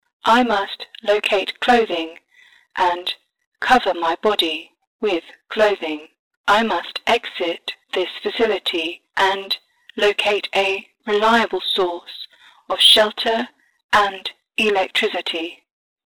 Robot Voice Audio Tutorial
Effects can be combined, like this example which adds the “mixed pitch” effect with the FFT frequency cutoff with a distortion preset called “bow curve 1”.
MELI-example-Mix-Pitch-FFT-Bow-Curve.mp3